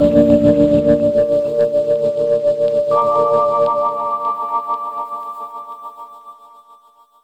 03 Smoky Joe Bb.wav